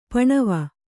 ♪ paṇava